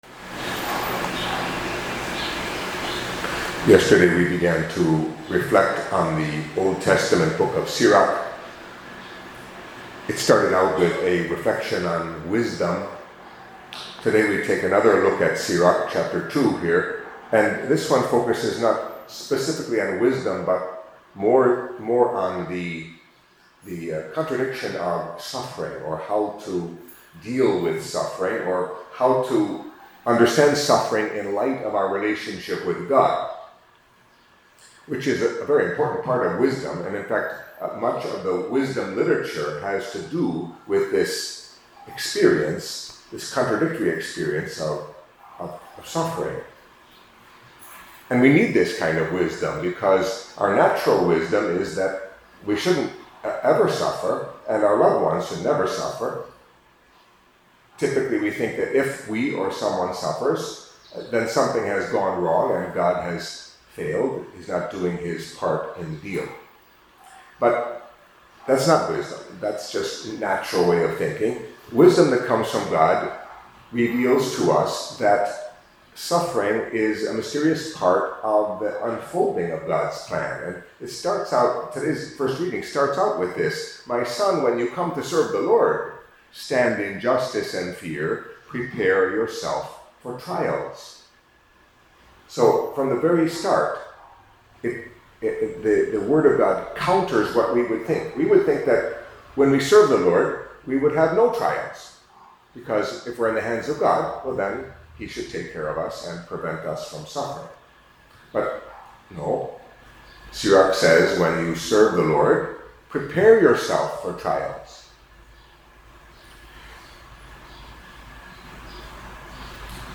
Catholic Mass homily for Tuesday of the Seventh Week in Ordinary Time